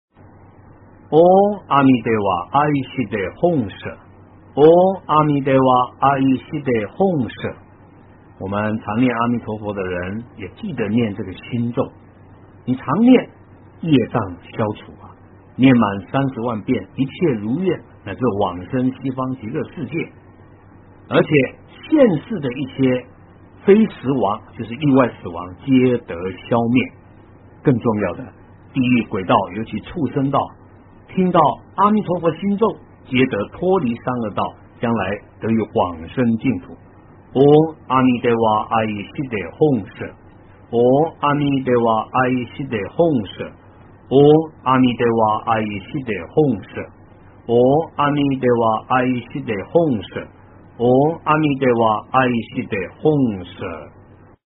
诵经
佛音 诵经 佛教音乐 返回列表 上一篇： 阿弥陀佛 下一篇： 阿弥陀经 相关文章 貧僧有話要說—序--释星云 貧僧有話要說—序--释星云...